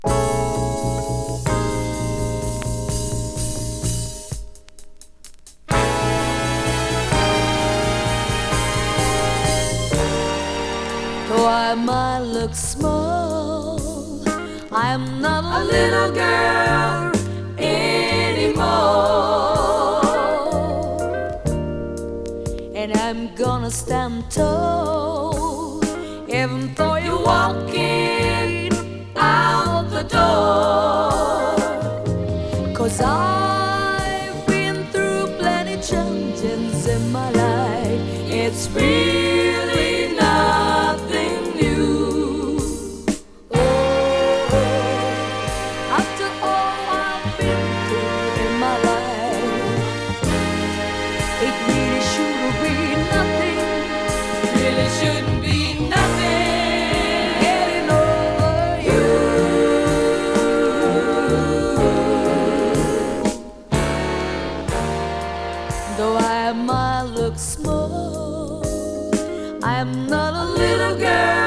和Rare Groove人気盤！
LA録音。